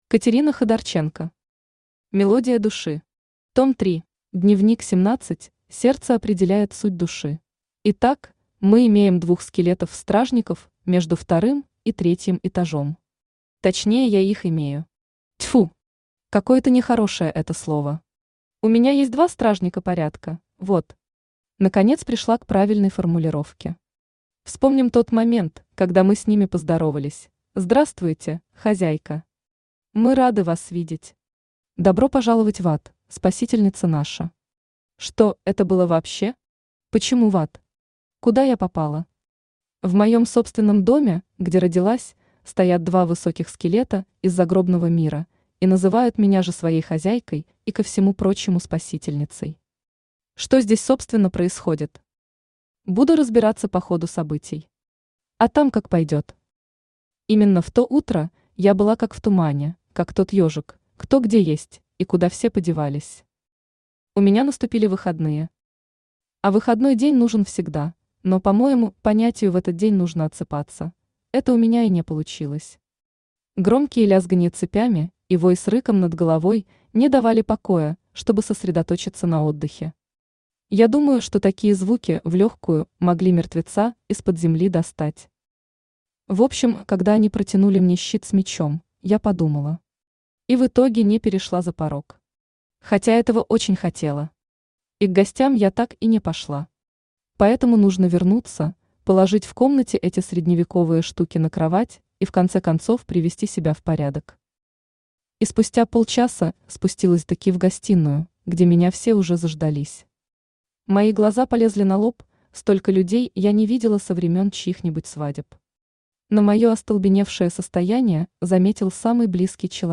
Аудиокнига Мелодия души. Том 3 | Библиотека аудиокниг
Том 3 Автор Катерина Ходорченко Читает аудиокнигу Авточтец ЛитРес.